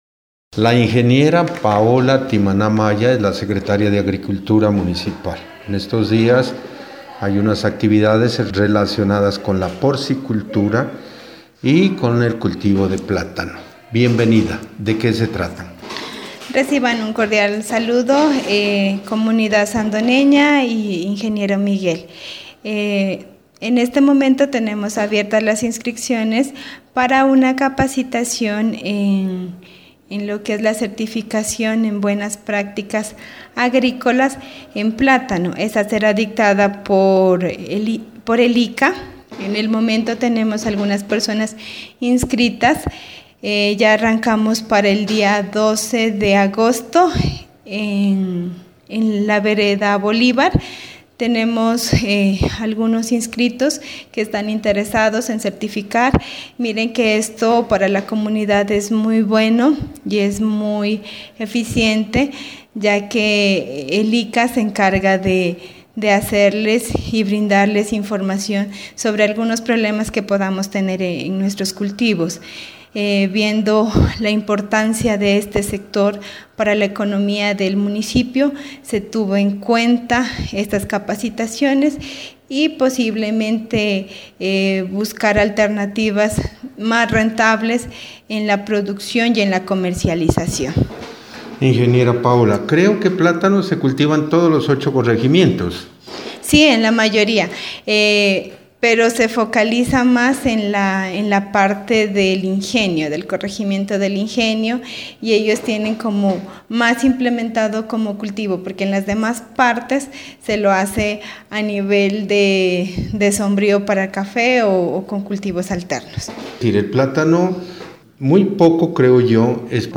Entrevista con la secretaria de agricultura Paola Timaná Maya.